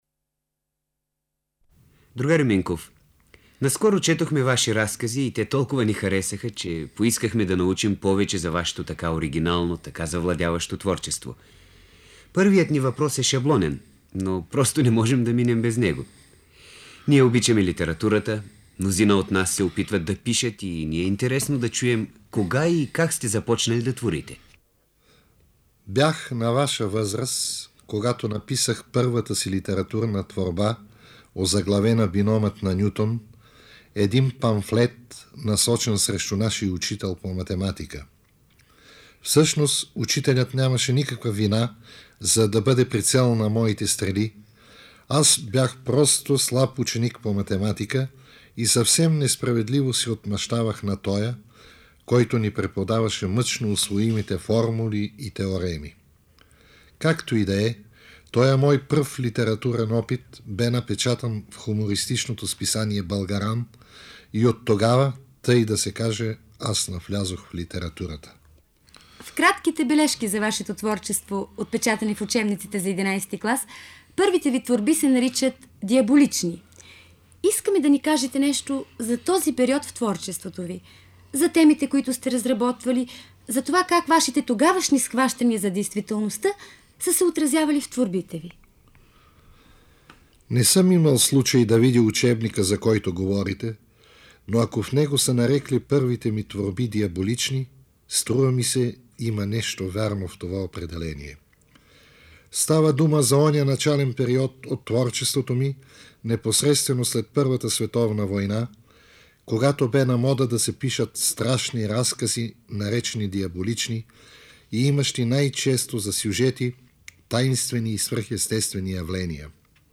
Интервю със Светослав Минков от 1966 г., запазено в „Златен фонд“ на БНР:
Очевидно е, че записът не е понесъл добре своите повече от 50 години, а интервюто видимо носи тежкия отпечатък на времето, в което е взимано. Въпреки това нищо не може да помрачи ведрия и приятен глас на големия писател: